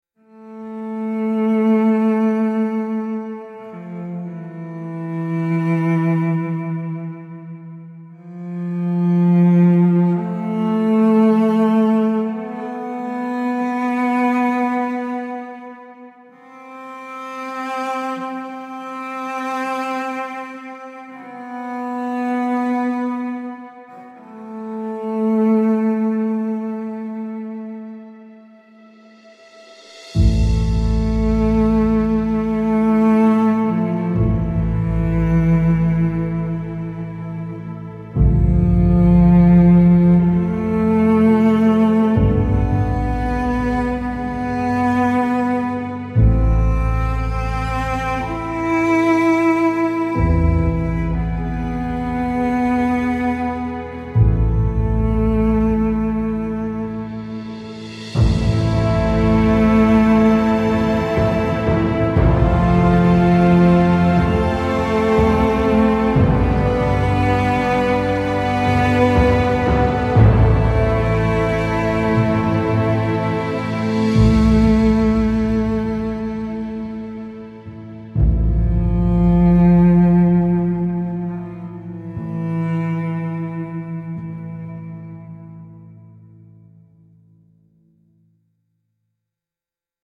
honorable tribute piece with gentle timpani rolls and deep cello melody